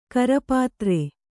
♪ karapātre